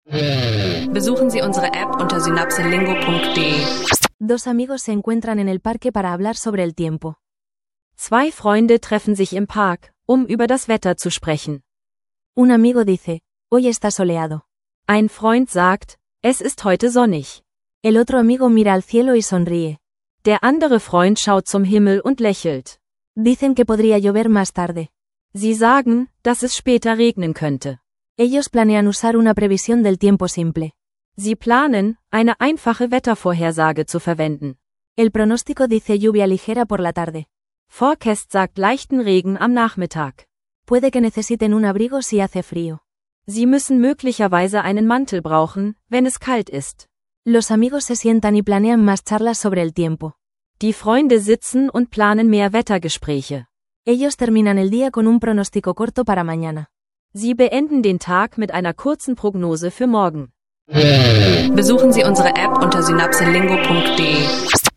In dieser Episode üben zwei Freunde einfache Wetterpraxen und kurze